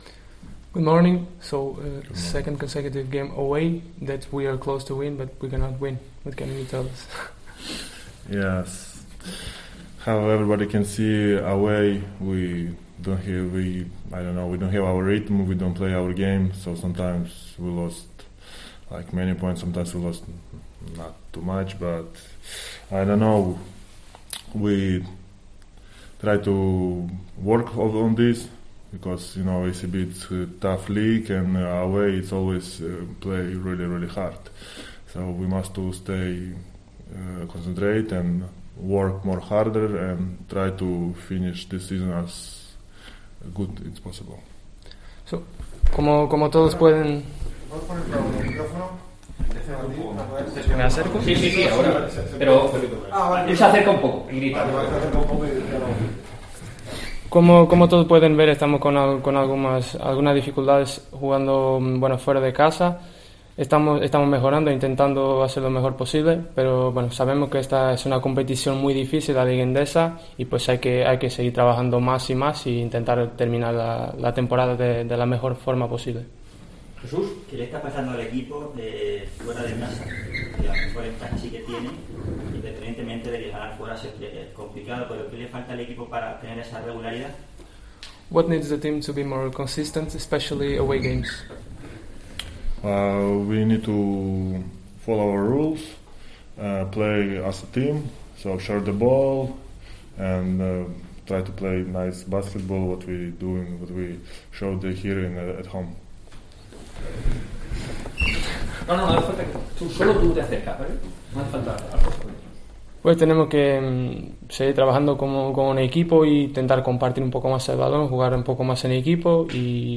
El pívot internacional ucraniano, de 30 años y 2,19 metros de estatura, se manifestó en esos términos en la rueda de prensa que ofreció este martes y que se aprovechó también para anunciar la renovación del patrocinio de Prezero, empresa de gestión de limpieza viaria, con la entidad universitaria.